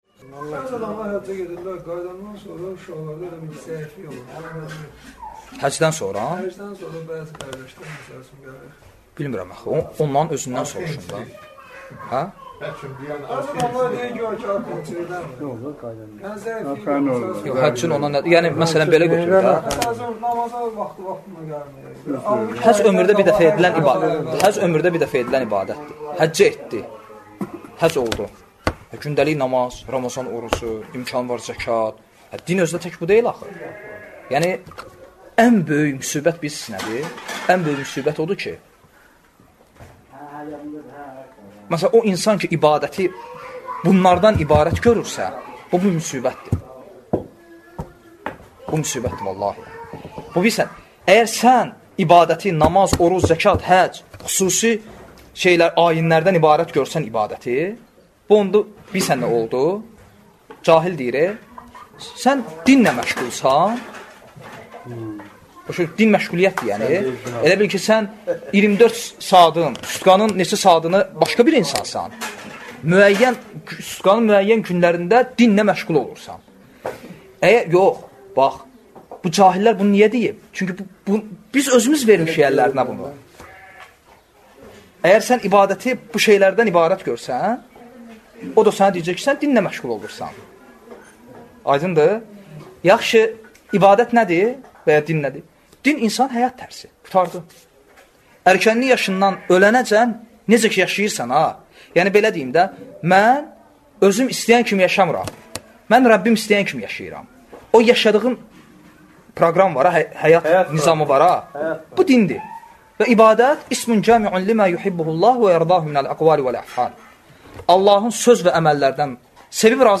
Dərslərdən alıntılar – 86 parça